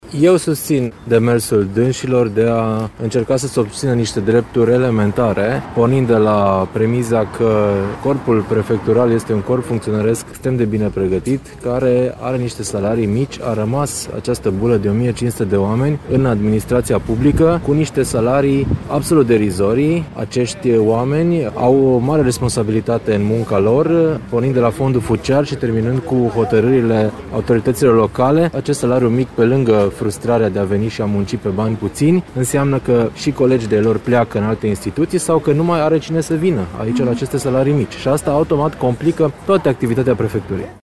Protestul de la Timişoara a fost susţinut şi de Prefectul de Timiș, Mihai Ritivoiu: